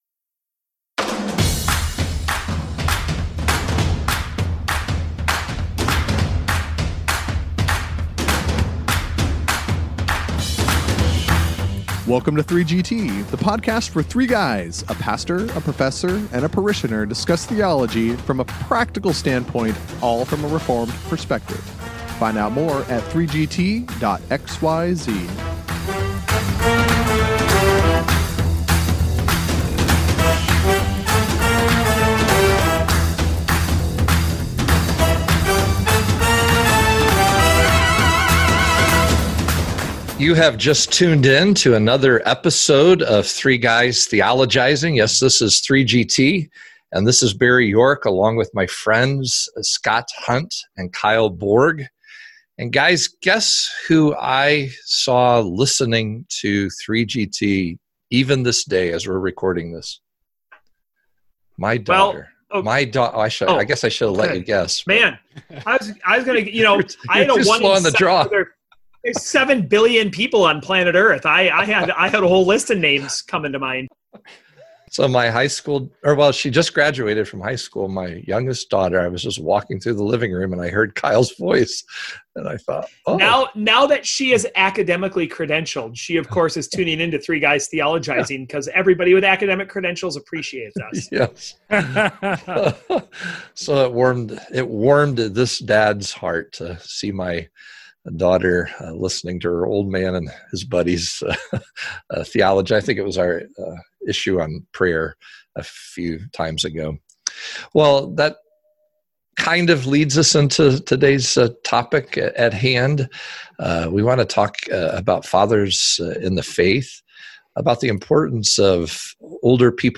And all along the way, they act like brothers as they get some of their customary pokes and jokes in.